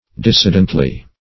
dissidently - definition of dissidently - synonyms, pronunciation, spelling from Free Dictionary Search Result for " dissidently" : The Collaborative International Dictionary of English v.0.48: Dissidently \Dis"si*dent*ly\, adv.
dissidently.mp3